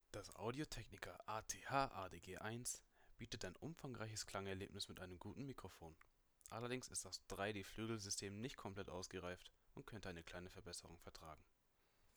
Das Mikrofon selbst bringt kristallklare Ergebnisse und kann sich damit zu den hohen Tieren in der Headset-Branche gesellen.
Das einzige Manko was hier gefunden werden kann, ist dass das Mikrofon sämtliche Berührungen am Headset, aufgrund von kaum vorhandenen aktiven Noise-Cancellings, selbst bei niedriger Empfindlichkeit überträgt und somit nur beim Richten des Headsets, schon mal die anderen TeamSpeak-Teilnehmer verärgert.
Hier einmal ein kleiner Mikrofon-Test, damit ihr euch ein Bild davon machen könnt:
Mic-Test-ATH-ADG1.wav